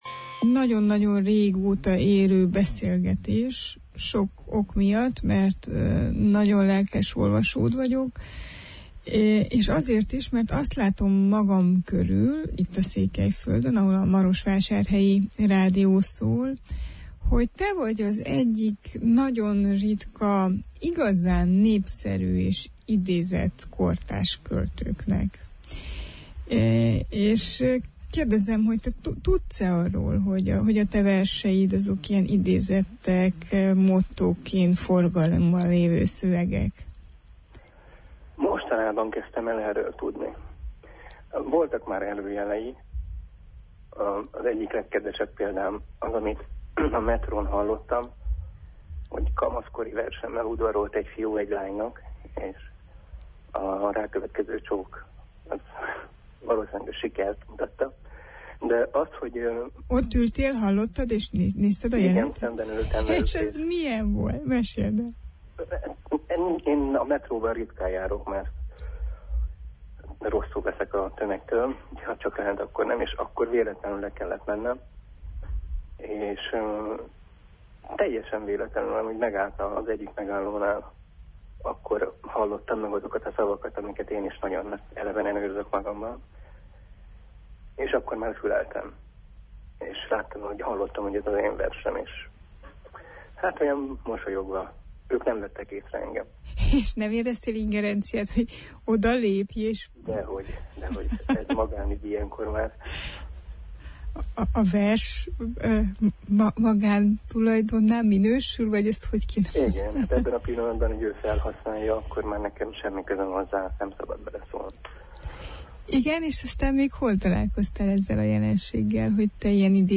Ha már címkéztek, legyen ezer címke – portrébeszélgetés Jónás Tamással